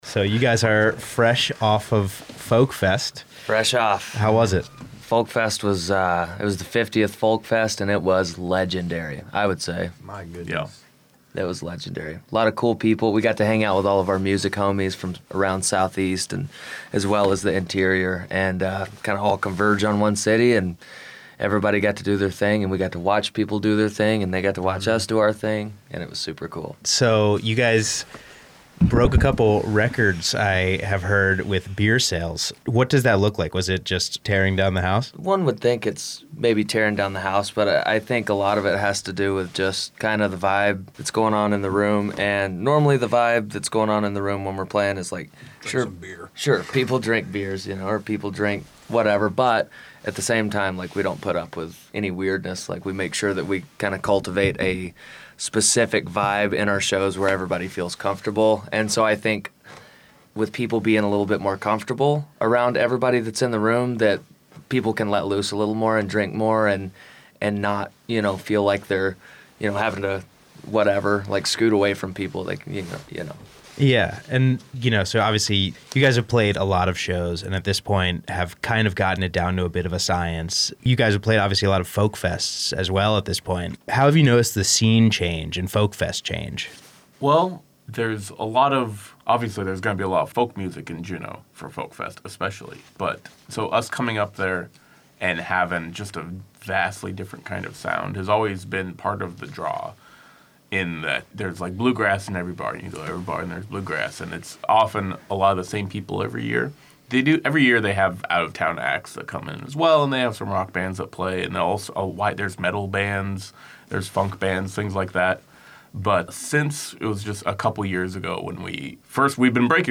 Note: This interview has been edited for length and clarity.